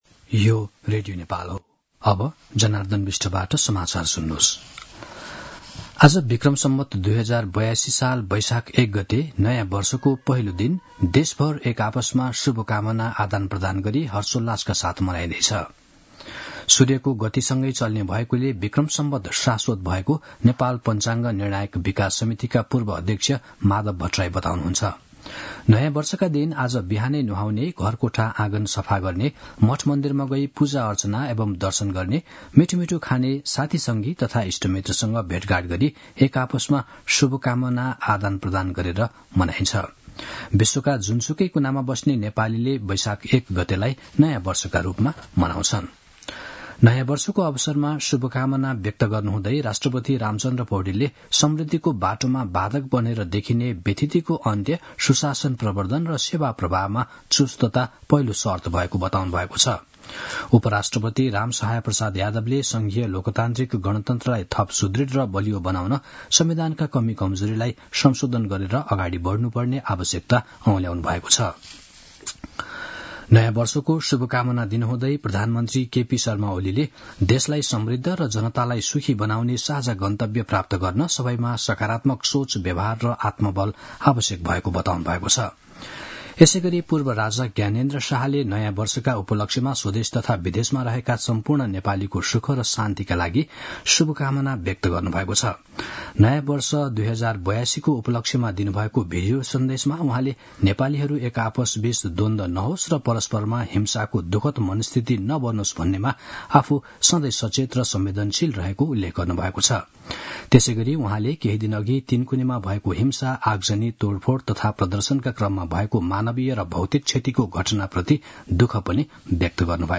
मध्यान्ह १२ बजेको नेपाली समाचार : १ वैशाख , २०८२
12-am-news.mp3